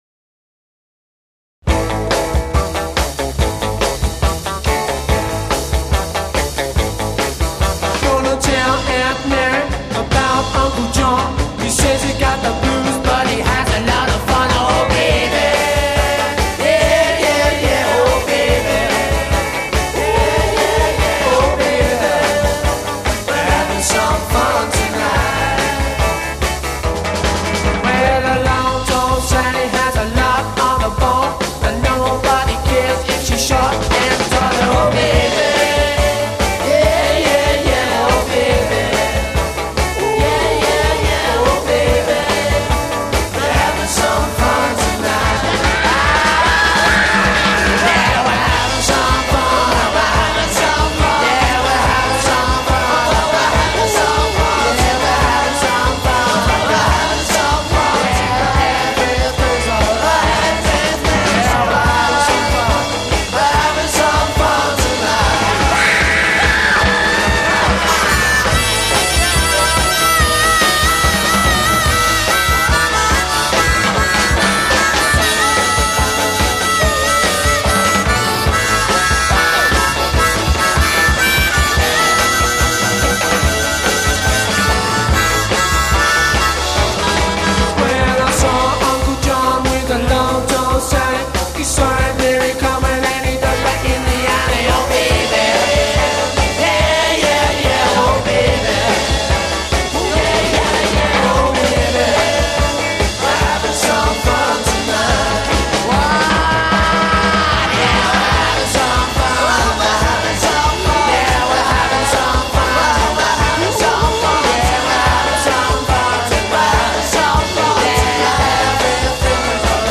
Intro 0:00 4 guitar establishes accompanying figure
4 double-tracked solo voice
B chorus : 12 responsorial organization of solo and chorus d
chorus : 12 harmonica solo